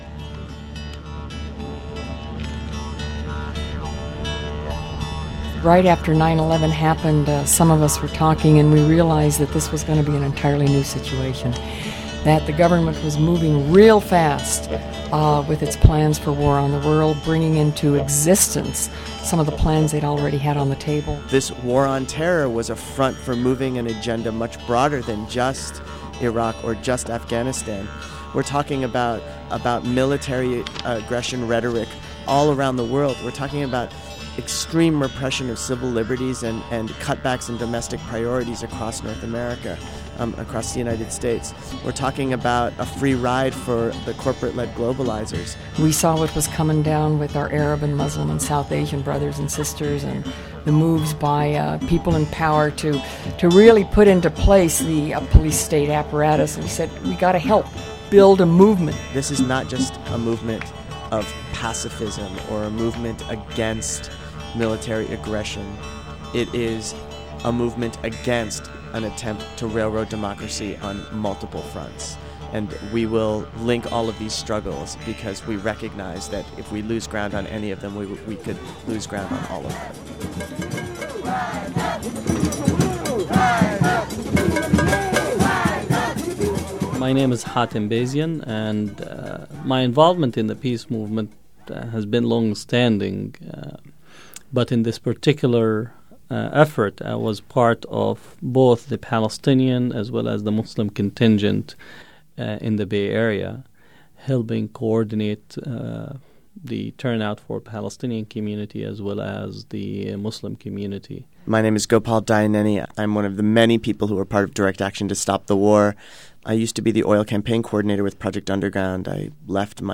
Their voices are weaved into sounds from the street, highlighting the emotions of the most intense anti war movement in the US.